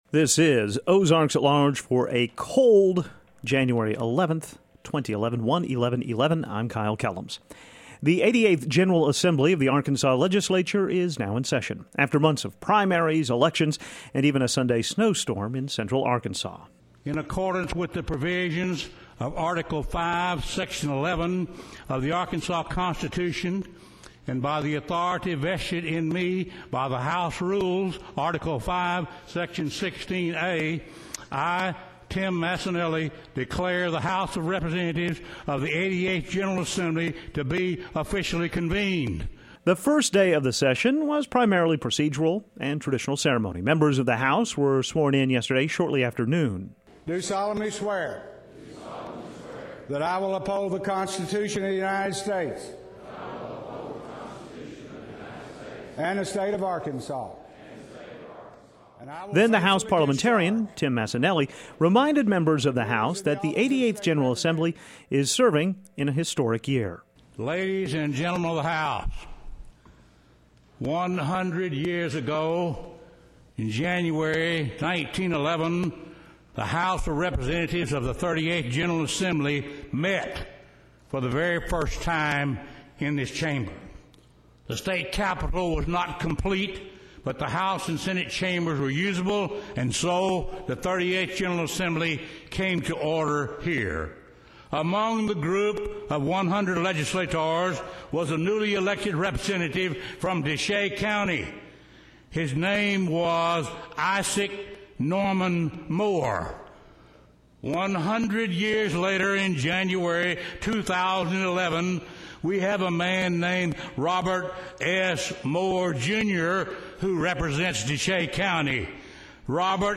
The Arkansas House opened the regular session yesterday and new Speaker of the House Robert Moore spoke to his fellow lawmakers. Hear all of the Speaker's address here.